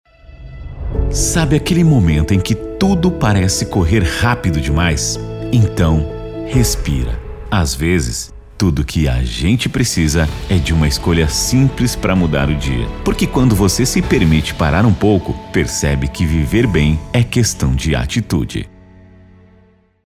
Interpretado: